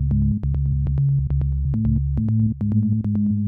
Index of /90_sSampleCDs/Best Service ProSamples vol.54 - Techno 138 BPM [AKAI] 1CD/Partition C/UK PROGRESSI
PSYCH LINE-L.wav